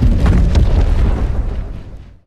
Wula_Disturber_Turret_Weapon_Explosive.wav